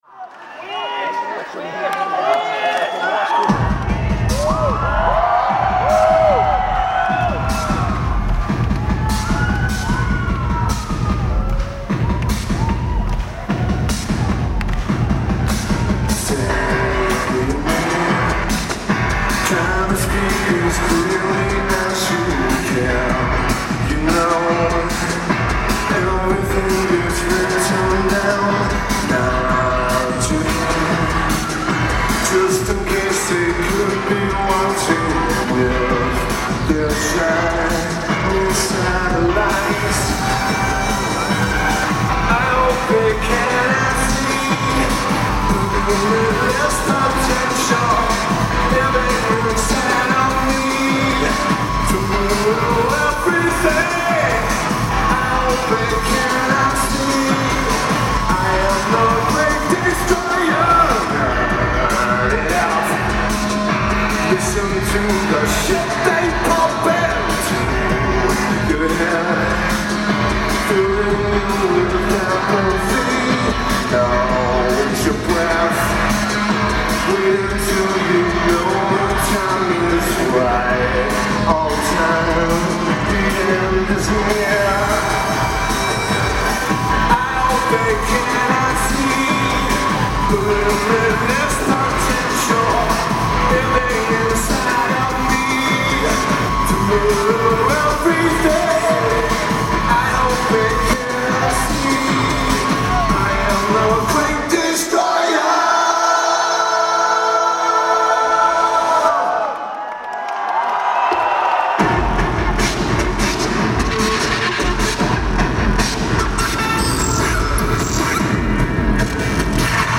Luzhniki Arena
Moscow Russia
Lineage: Audio - AUD (Panasonic RP-VC201 + Sony MZ-NH1)